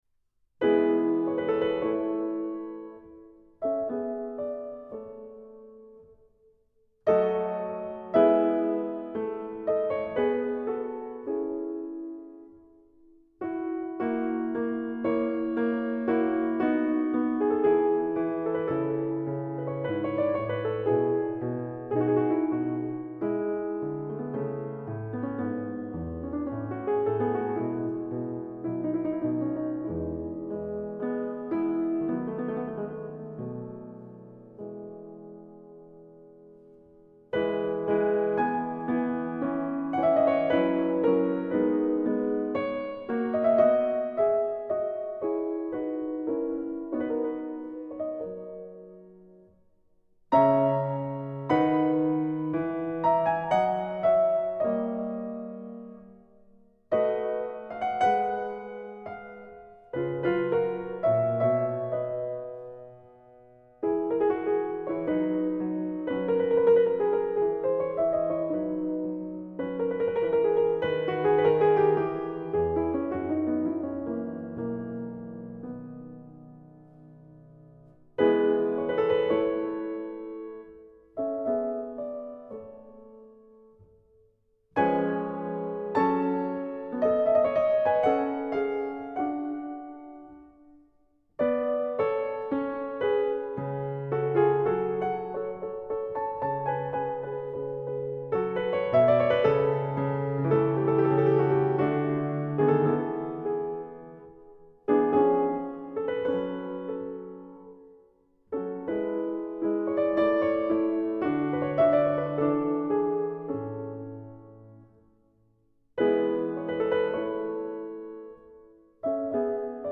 Genre : Classical